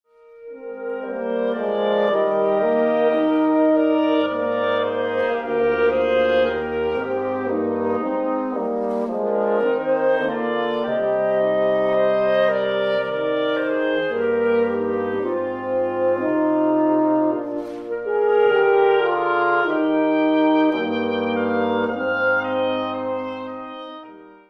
Wind Quintet
Bassoon , Clarinet , Flute , Horn , Oboe